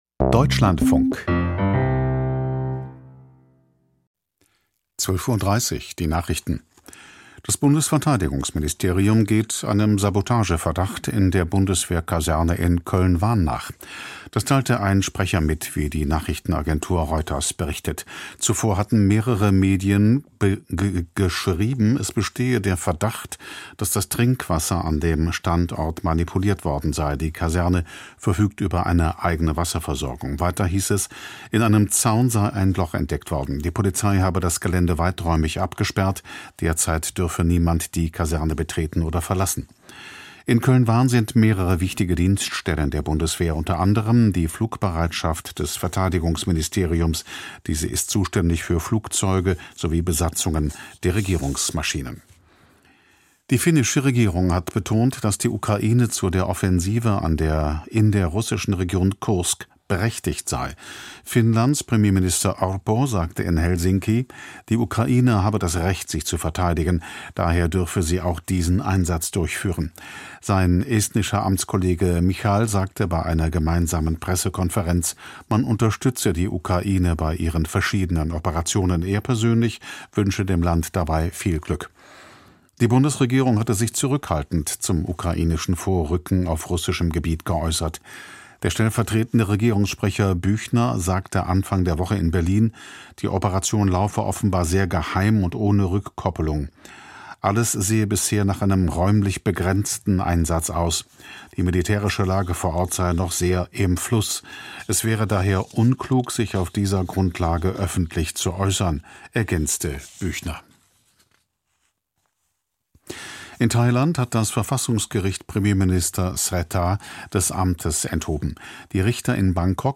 Lage in Russland und der Ukraine: Interview